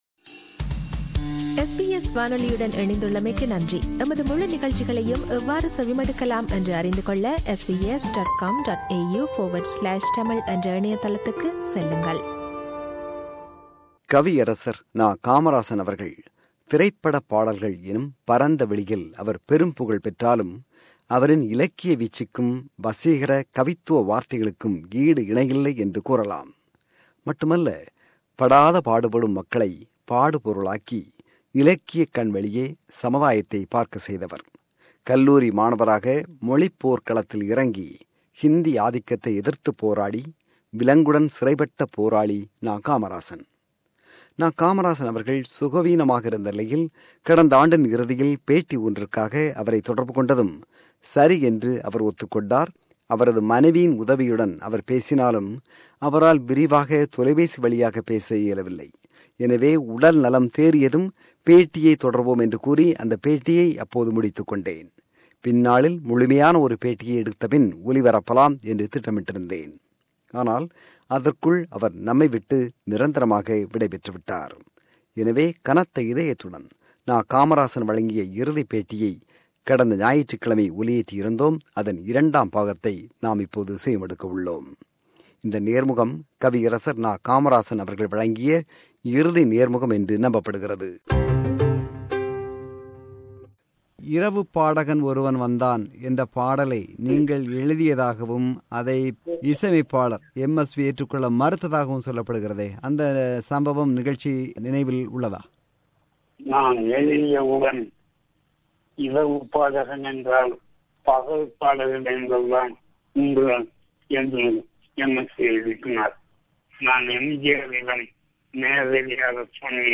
An exclusive interview with Poet N.Kamarasan – Part 2
This is the second (final) part of the interview.